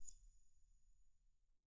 Minecraft Version Minecraft Version 1.21.5 Latest Release | Latest Snapshot 1.21.5 / assets / minecraft / sounds / block / eyeblossom / eyeblossom_open2.ogg Compare With Compare With Latest Release | Latest Snapshot
eyeblossom_open2.ogg